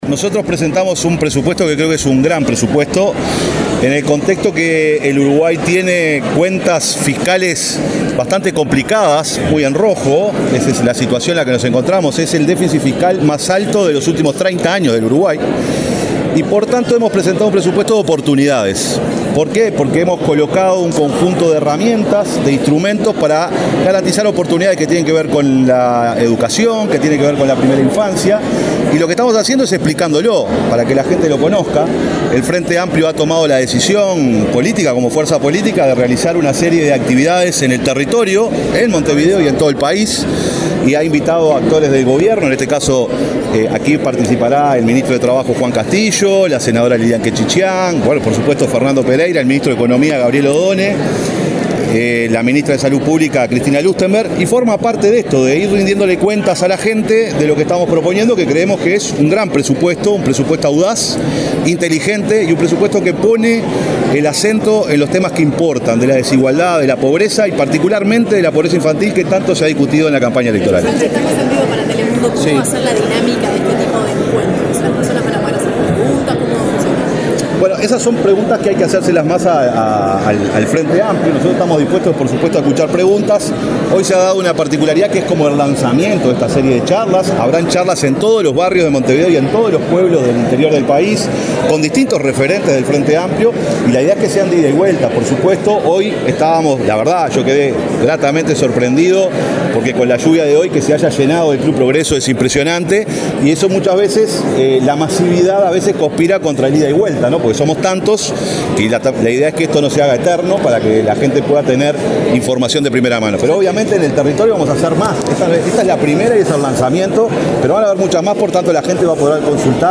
La palabra del funcionario de Presidencia fue en el marco de la presentación del Presupuesto en el barrio La Teja ante diversos ministros y el presidente de la coalición de izquierda.